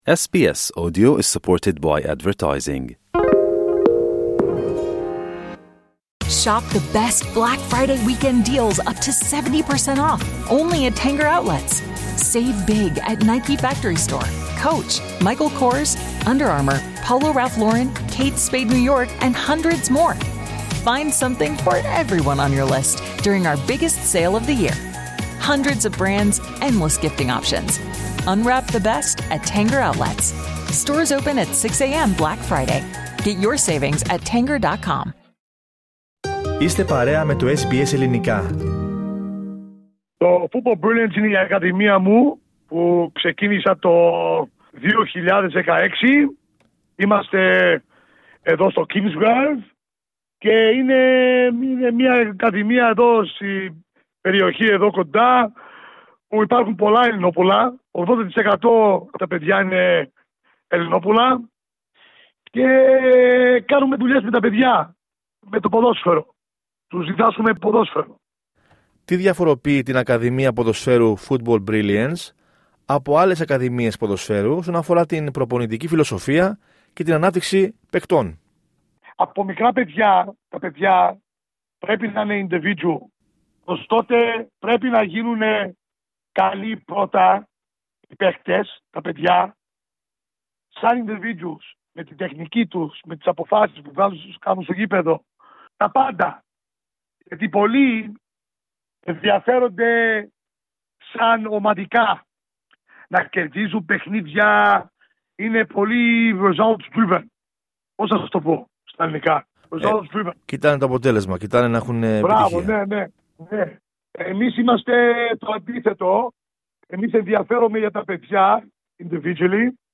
Οι πρωταγωνιστές του έπους του 2004 μιλούν στο SBS